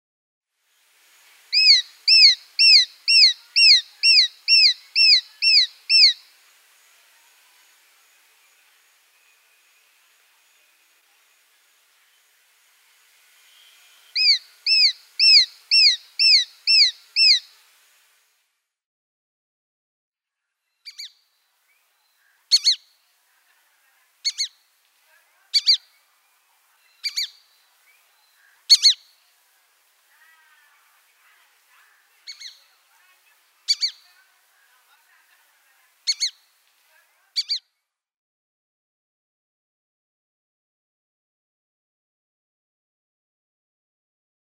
نام فارسی: پیغوی کوچک
نام انگلیسی: Shikra
نام علمی: Accipiter badius
05.Shikra.mp3